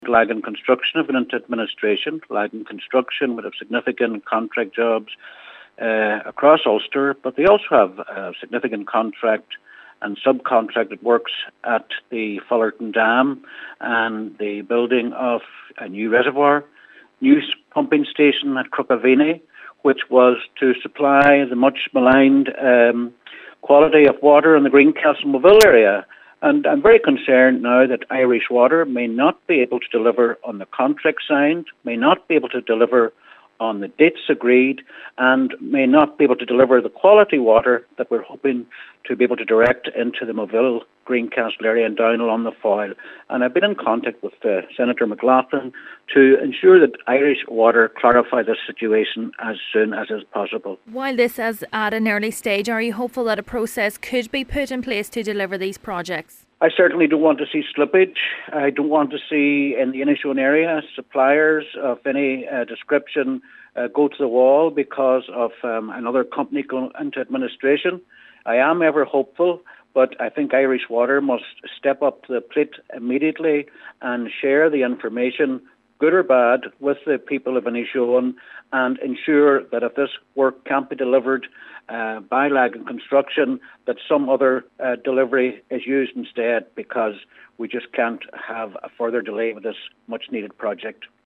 Inishowen Councillor Albert Doherty fears Irish Water may not be able to deliver on the project going forward and he’s calling for clarity: